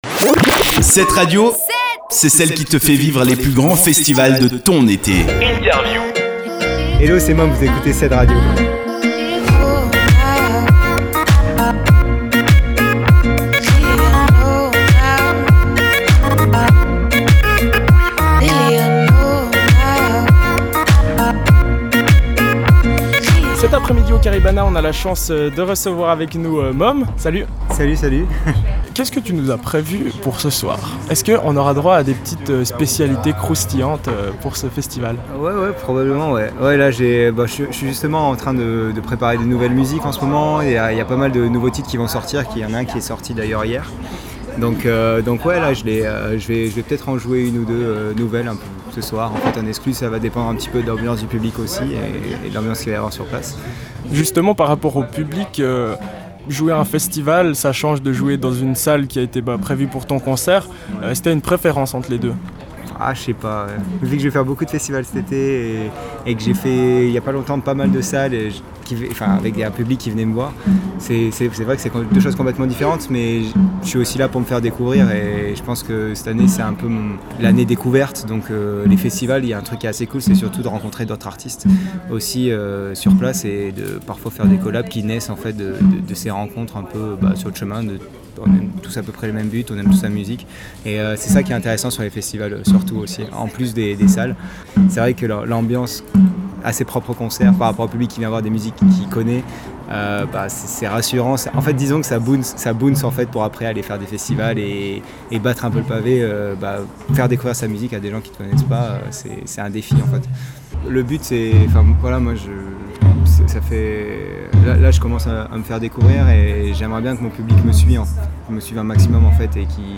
Møme, Caribana Festival 2017
INTERVIEW-MOME.mp3